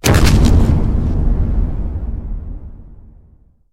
planetExplode.wav